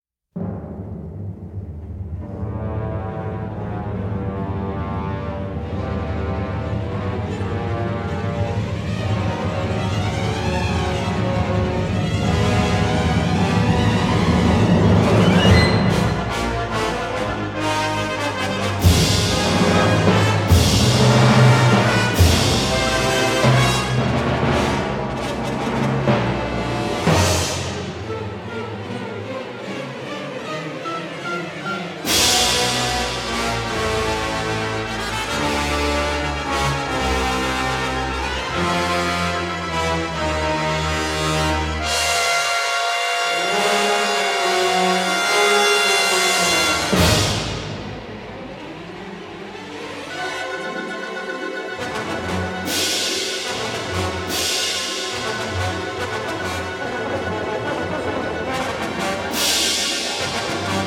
a rerecording of music